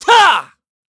Siegfried-Vox_Attack4_kr.wav